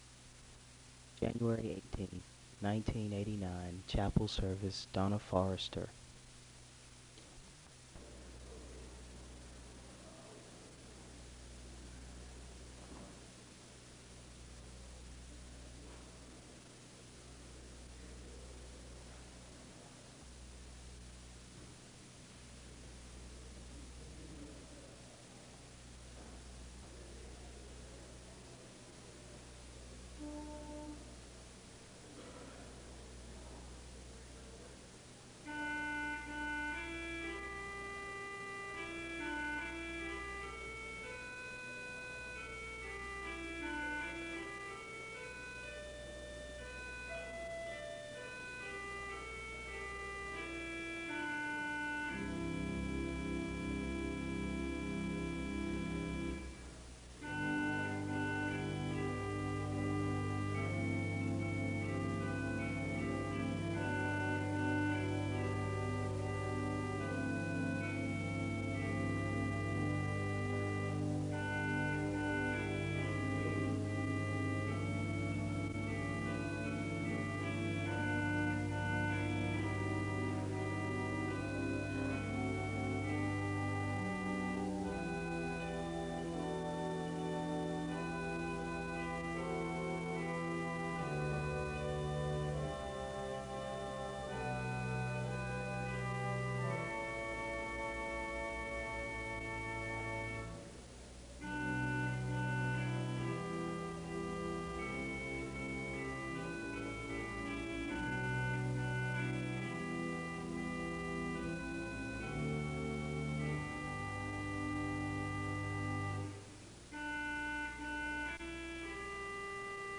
Location Wake Forest (N.C.)